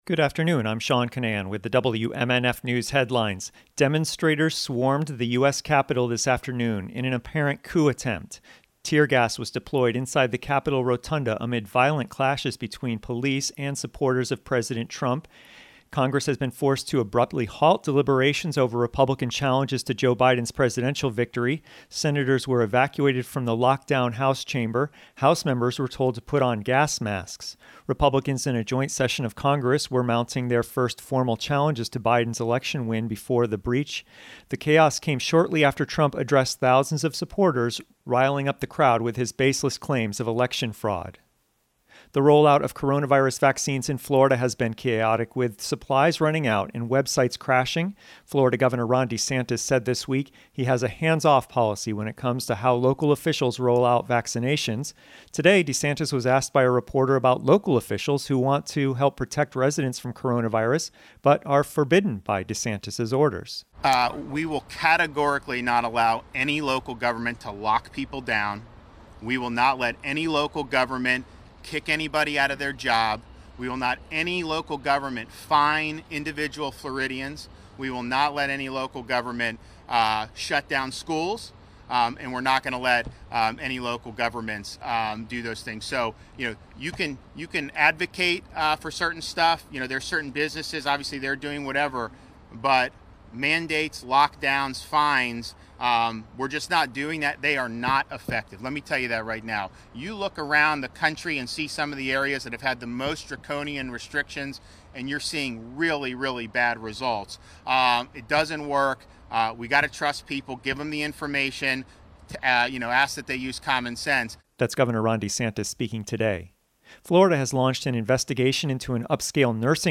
Here is the WMNF headline newscast we prepared for 3:30 p.m. on 6 January 2021, but it didn’t air because it was preempted by NPR’s coverage of the day’s insurrection news: